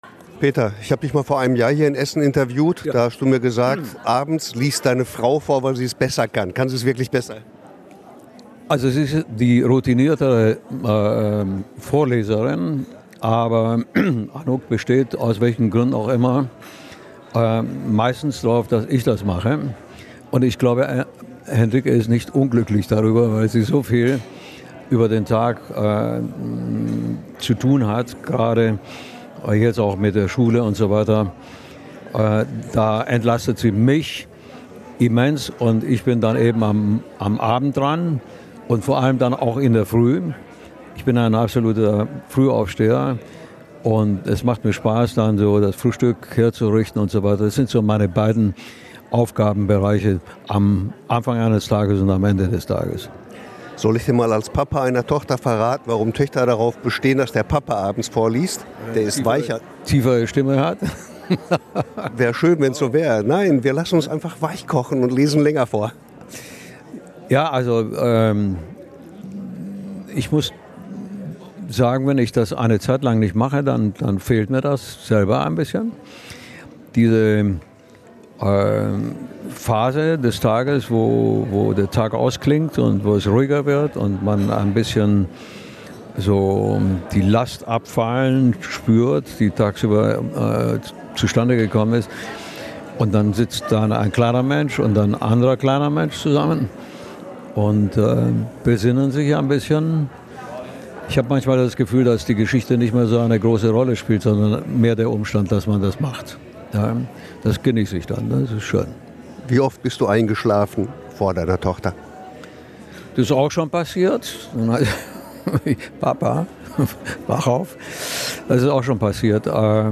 Im GOP in Essen läuft bald das neue Kindermusical "Anouk" mit Musik von Peter Maffay. Im Radio Essen Interview hat der Musiker erzählt, worum es geht.